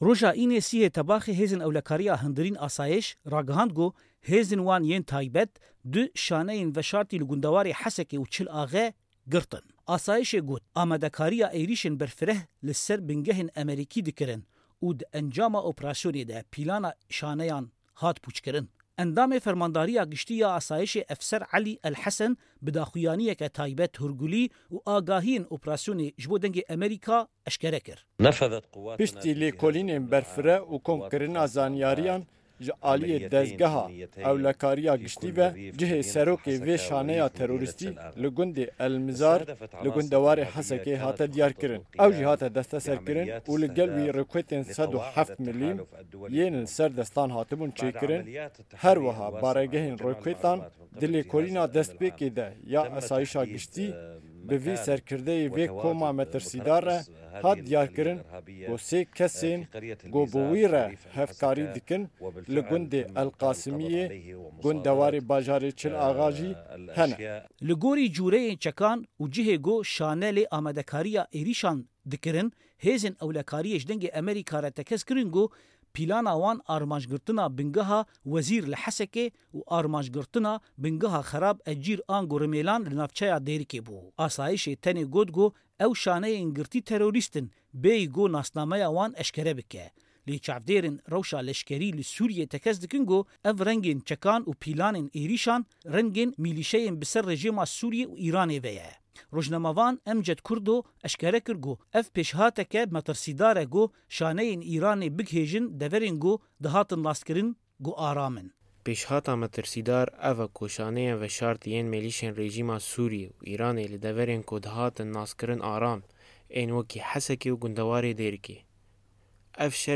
Raporta Qamîşlo - Mîlîşyayên Îran li Sûriyê Çalak Dibe.wav